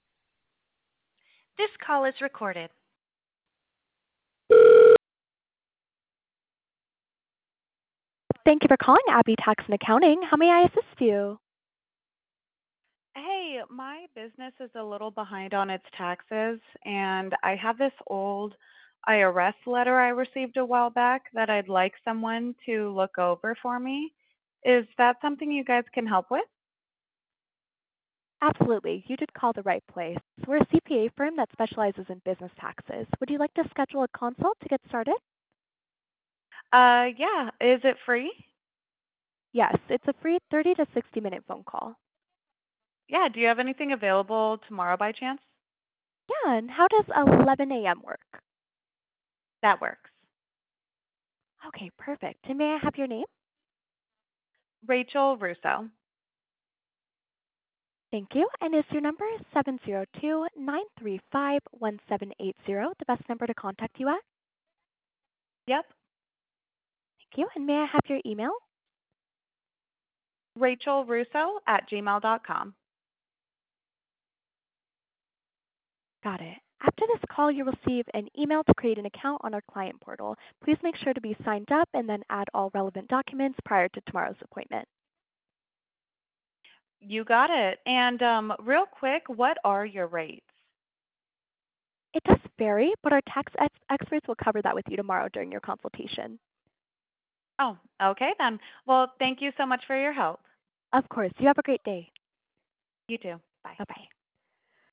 Listen to a demo to hear Abby’s virtual receptionist taking real calls like yours!
HUMAN RECEPTIONIST